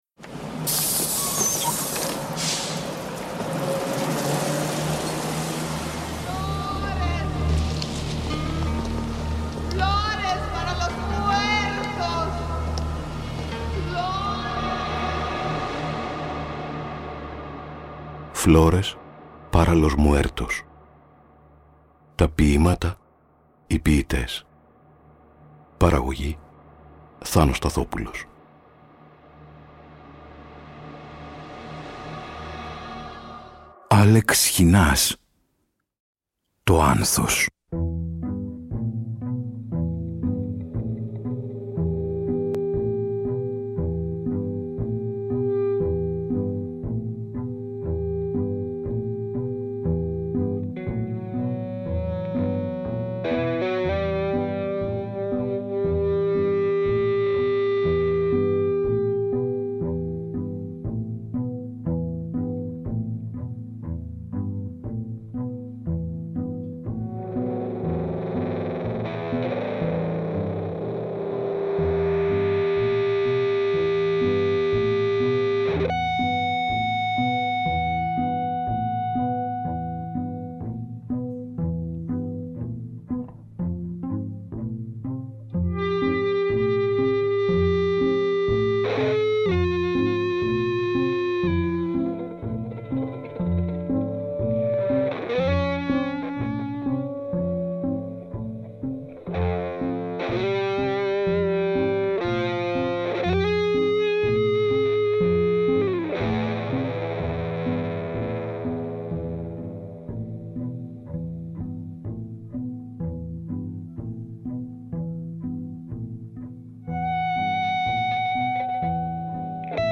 (Τραγούδια και μουσική για το χρόνο)